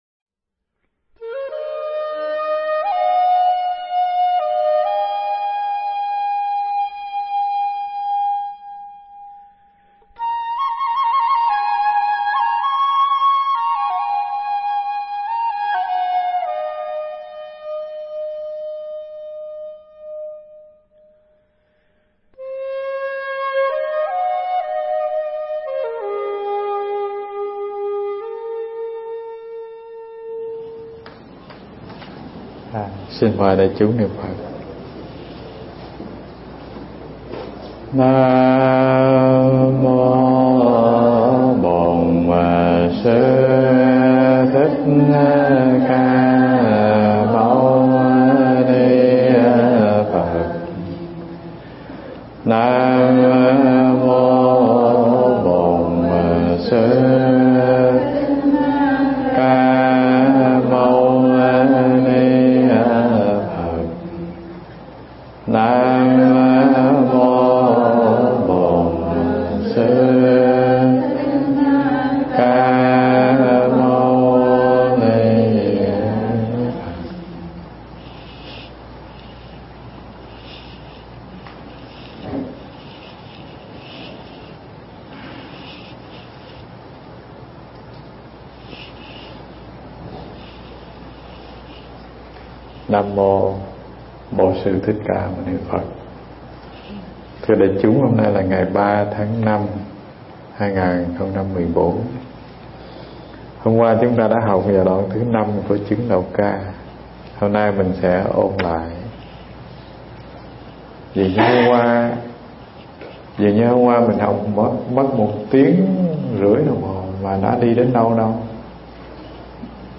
Nghe mp3 pháp thoại Chứng Đạo Ca 09 Chưa Từng Thêm Bớt Phần 3